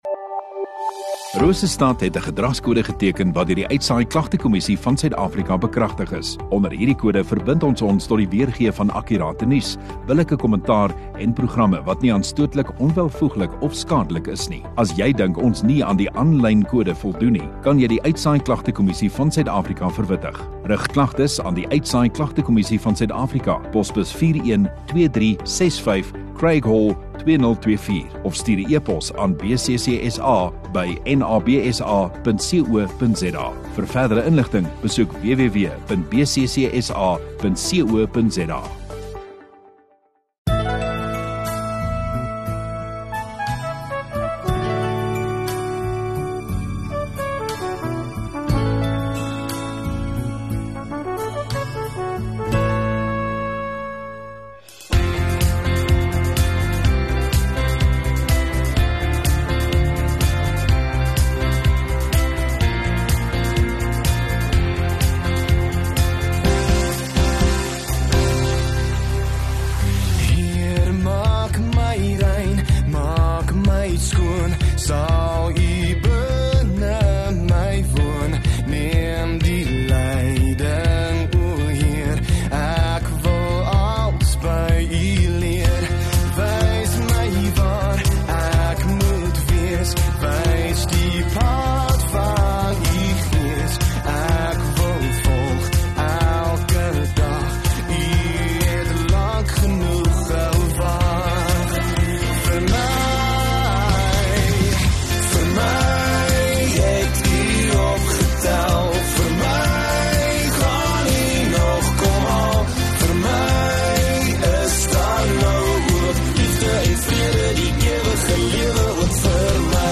7 Mar Saterdag Oggenddiens